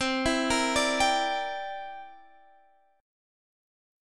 C9 chord